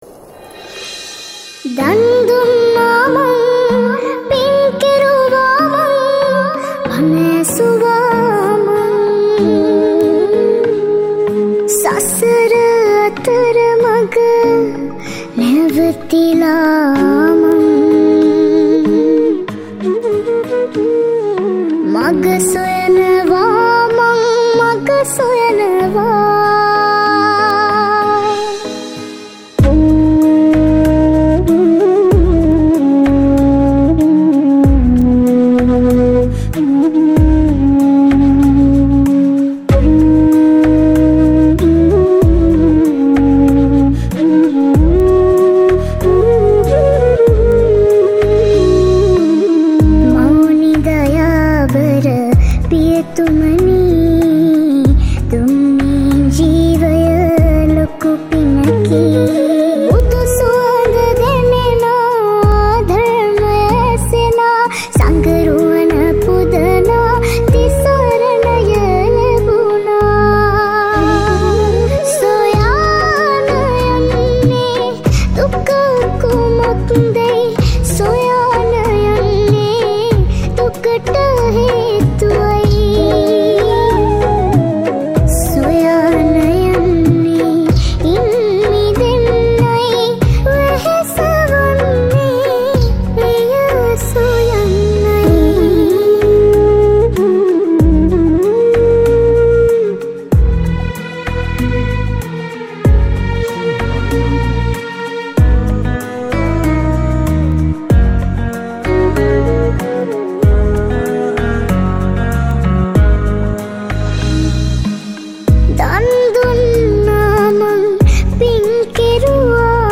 Flute
Guitar